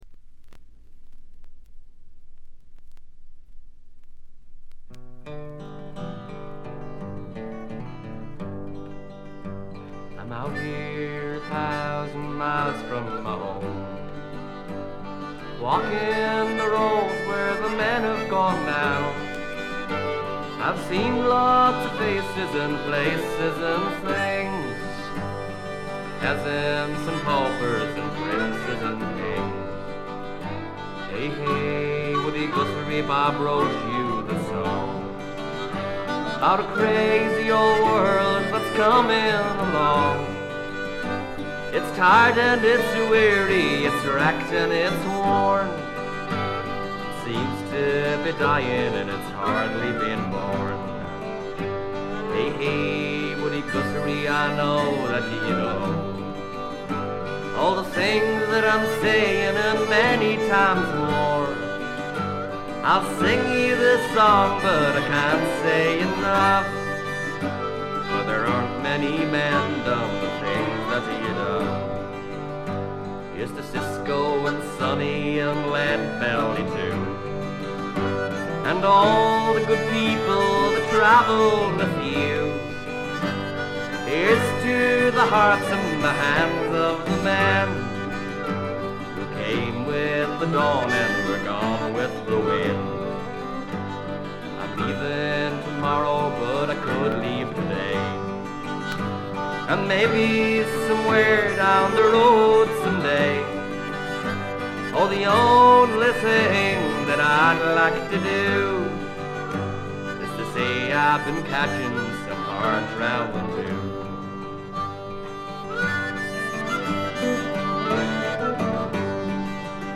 中身は哀切なヴォイスが切々と迫る名盤。
試聴曲は現品からの取り込み音源です。